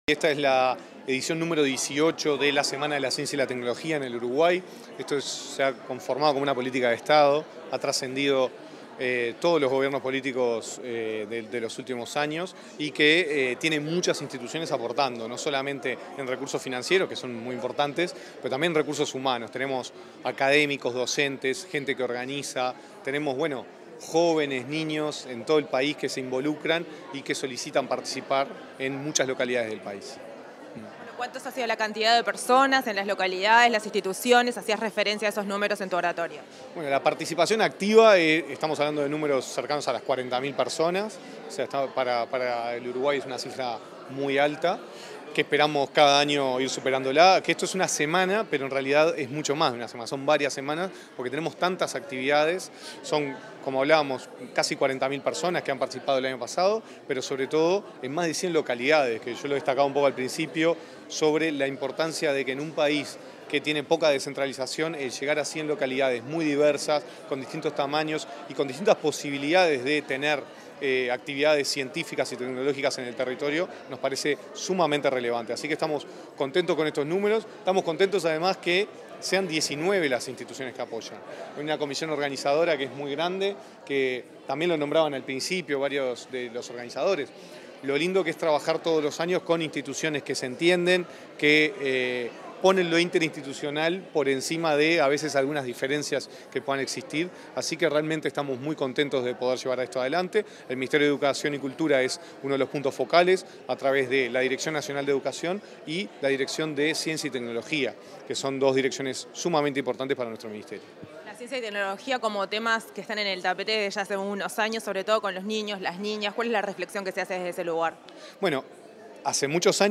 Entrevista al director nacional de Educación, Gonzalo Baroni